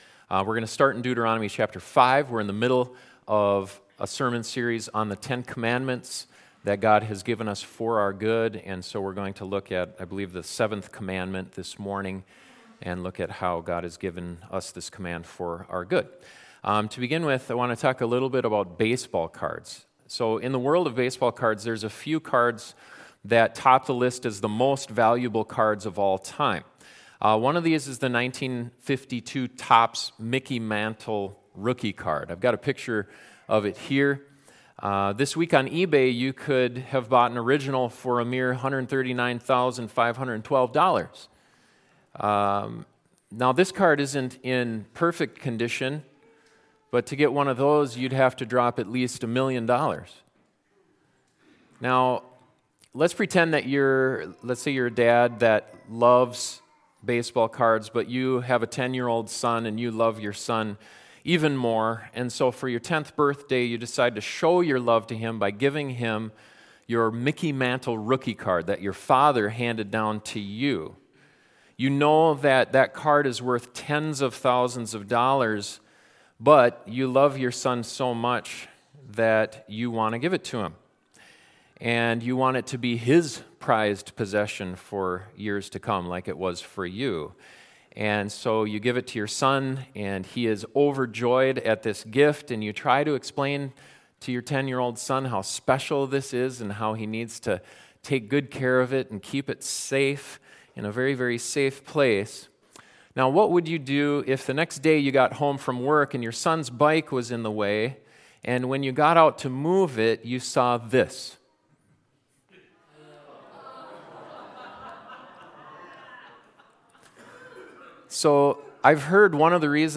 This sermon looks at how that command was given only for our good so that we can fully enjoy God’s gift of marriage and sex and experience the ultimate fulfillment it points us to.